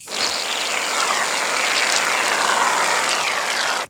SHAVE CREAM.WAV